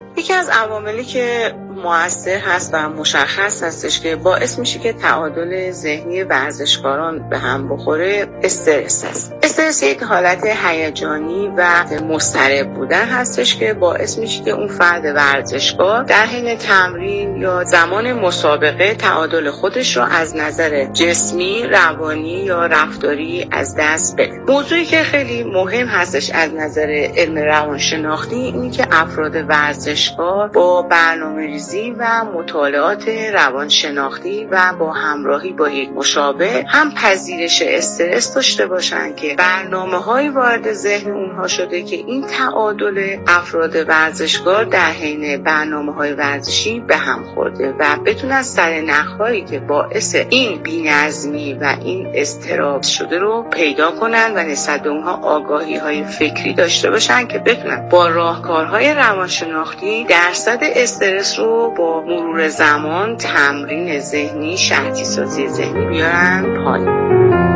/گفت و گوی رادیویی/